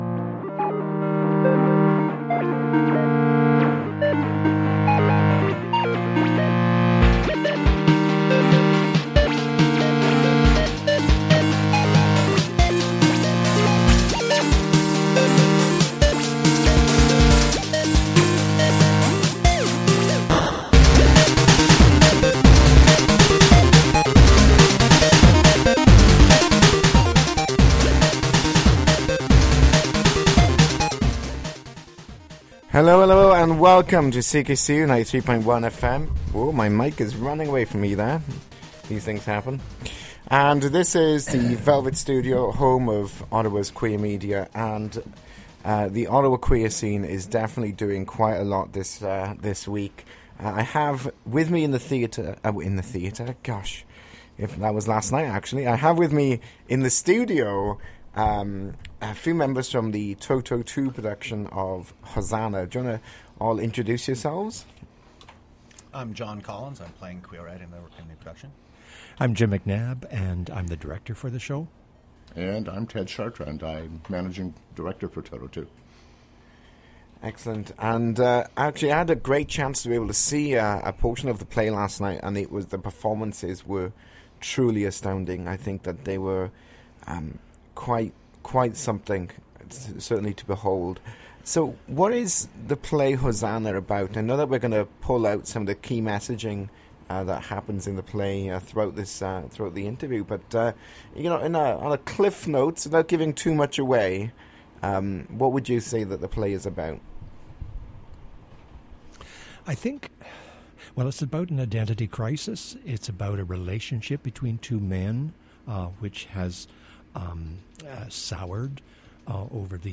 The Velvet Studio interview the cast and crew of the local production of Hosanna